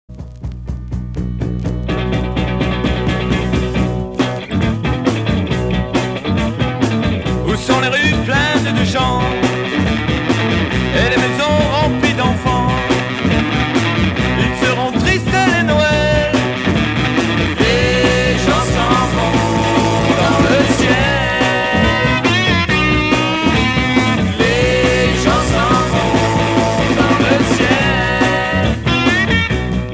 Rock mod 60's